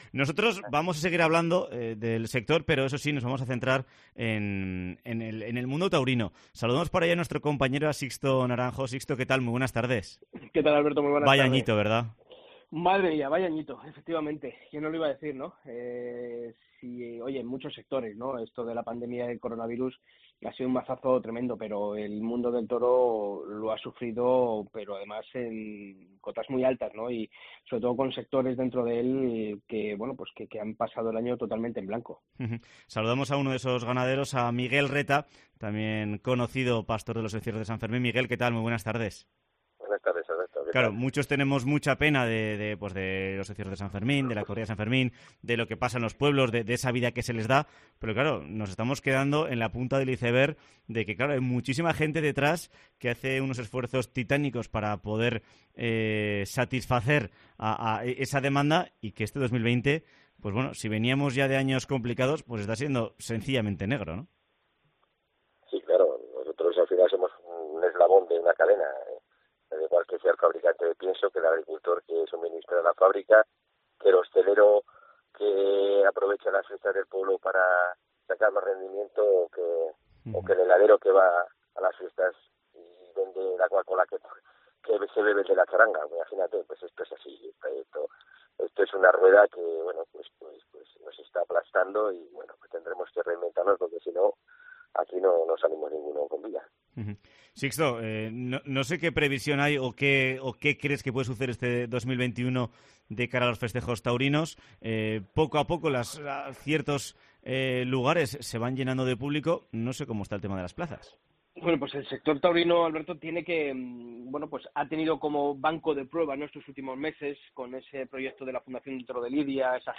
El ganadero navarro analiza en COPE Pamplona la delicada situación por la que está pasando la cabaña de bravo por culpa de la pandemia y sus...